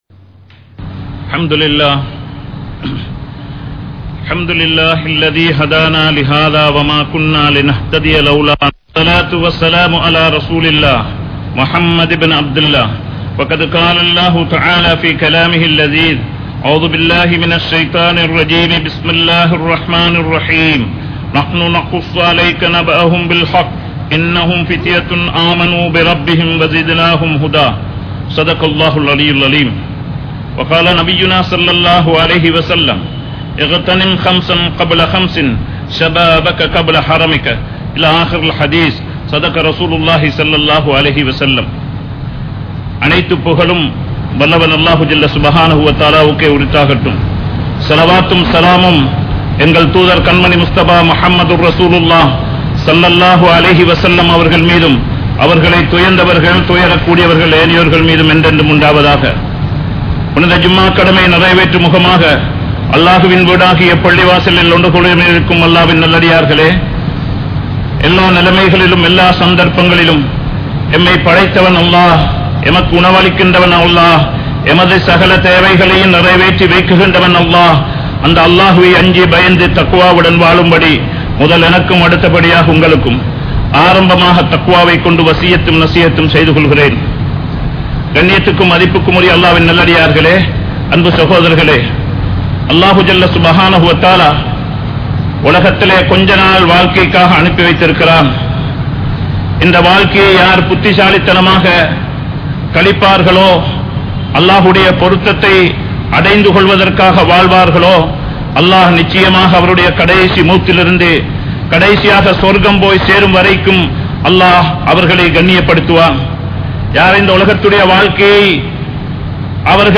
Vaalifarhale! Paavaththai Vittu Vidungal (வாலிபர்களே! பாவத்தை விட்டு விடுங்கள்) | Audio Bayans | All Ceylon Muslim Youth Community | Addalaichenai
Kollupitty Jumua Masjith